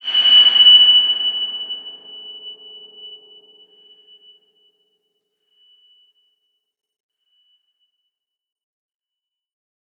X_BasicBells-F#5-mf.wav